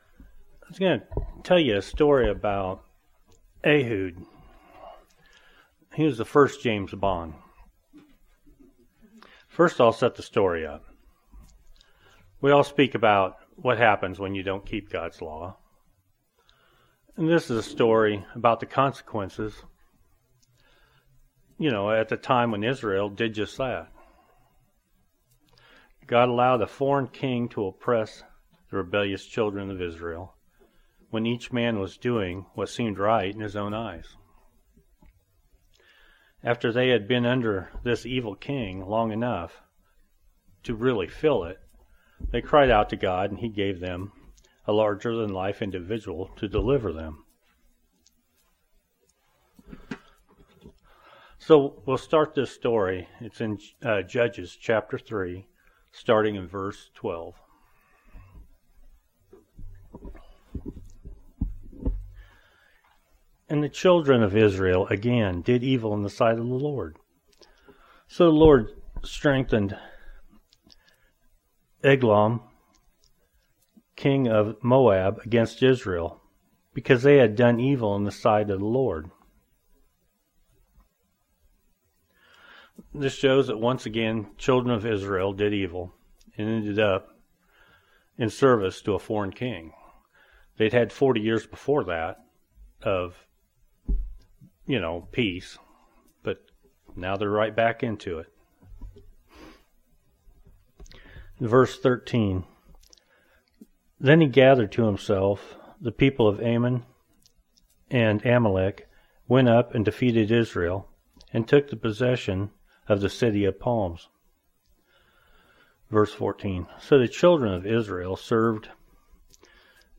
Sermons Ehud Ehud A Deliverer Sent by God
Given in Northwest Arkansas